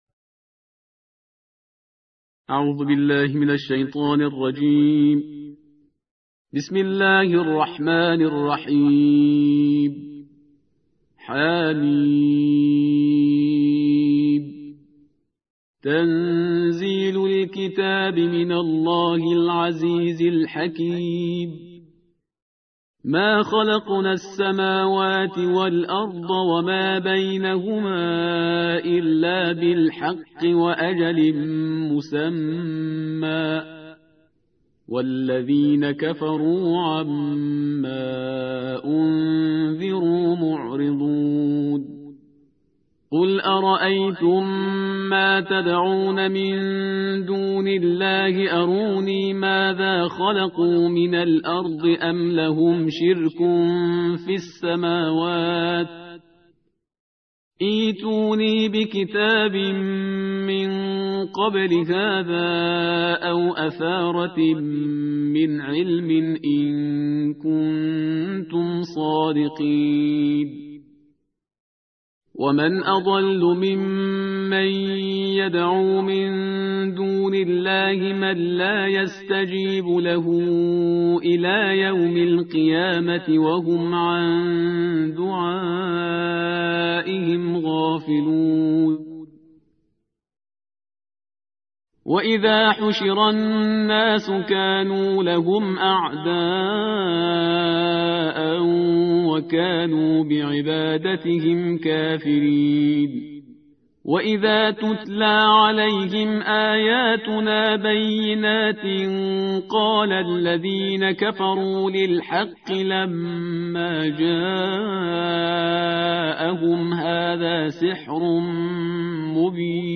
تلاوت جزء ۲۶ قرآن مجید با صدای استاد شهریار پرهیزکار